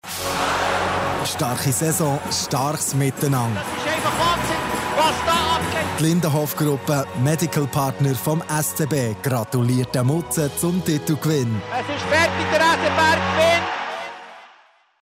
Radiospot, der Ostersonntag und –montag ausgestrahlt worden ist.